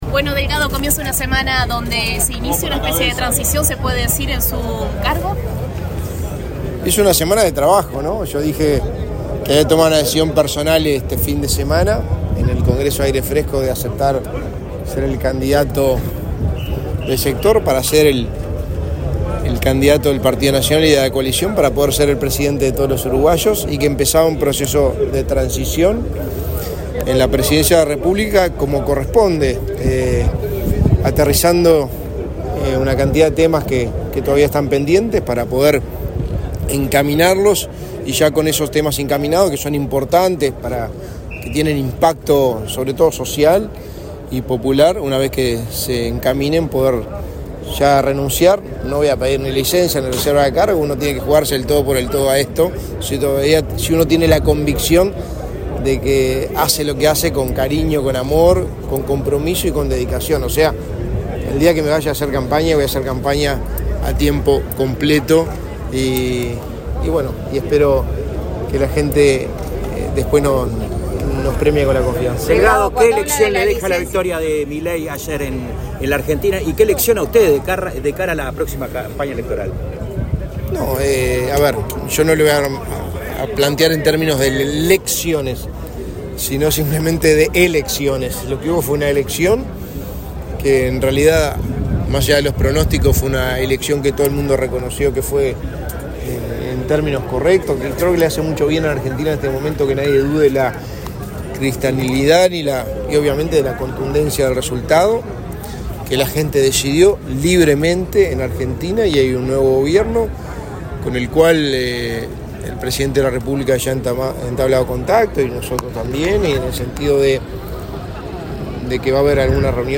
Declaraciones del secretario de Presidencia, Álvaro Delgado
El secretario de Presidencia, Álvaro Delgado, dialogó con la prensa, luego de participar del acto por el Día del Policía Caído en el Cumplimiento del